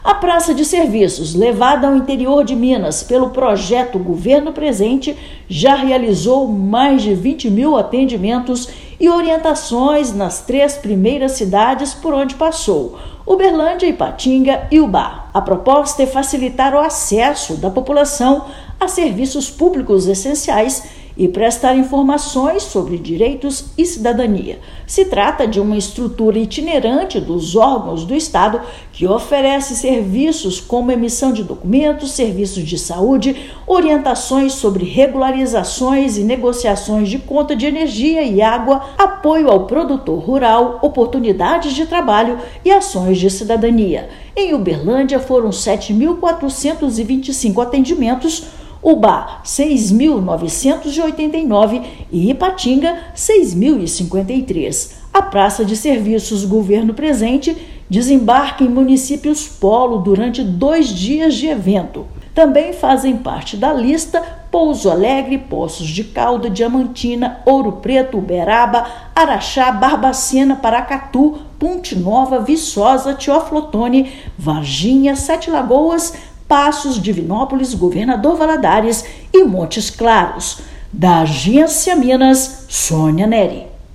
Iniciativa percorre diversas cidades mineiras, facilitando o acesso da população a serviços públicos essenciais e garantindo que todos sejam atendidos com respeito, cuidado e acolhimento. Ouça matéria de rádio.